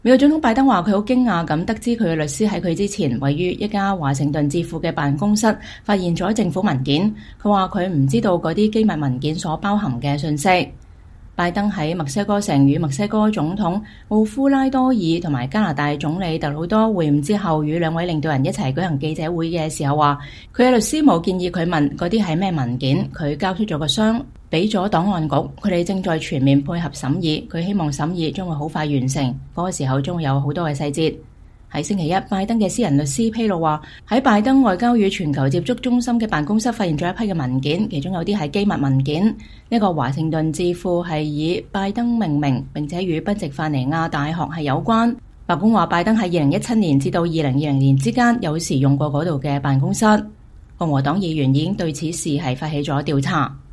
美國總統拜登、墨西哥總統奧夫拉多爾與加拿大總理特魯多在墨西哥城舉行的北美領導人峰會結束後聯合舉行記者會。(2023年1月10日)